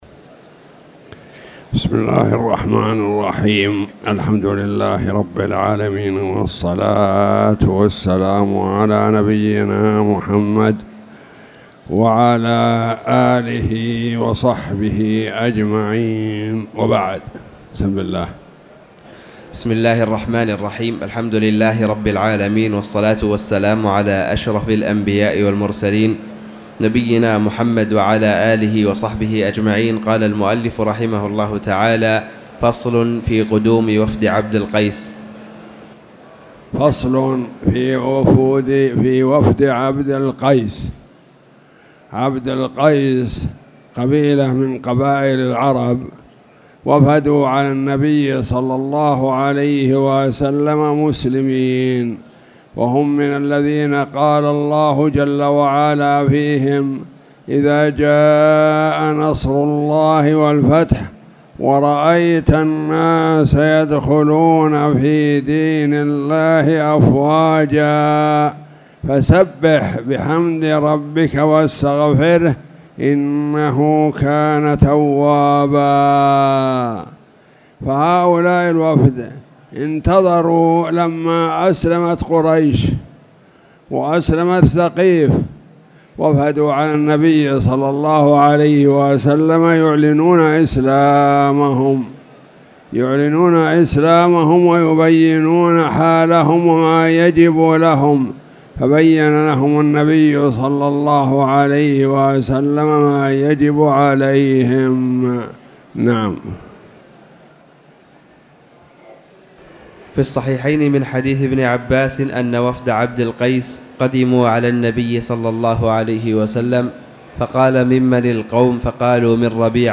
تاريخ النشر ٢٧ جمادى الأولى ١٤٣٨ هـ المكان: المسجد الحرام الشيخ